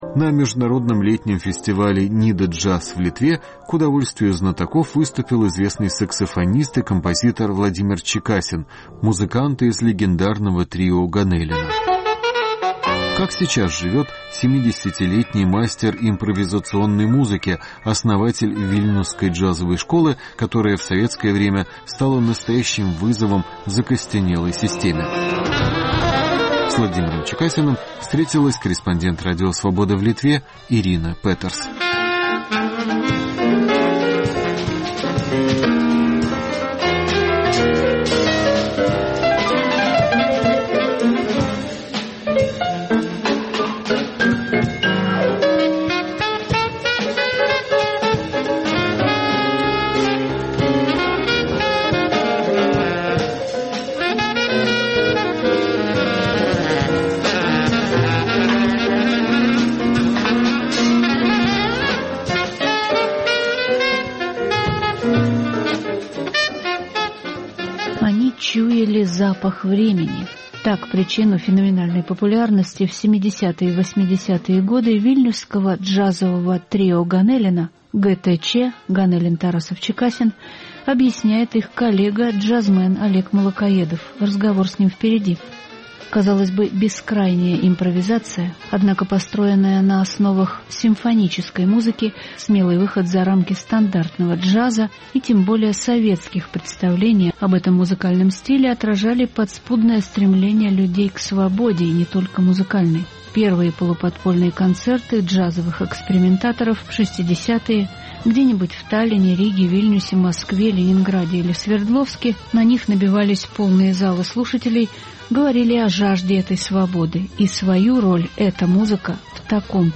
Разговор с джазовым музыкантом Владимиром Чекасиным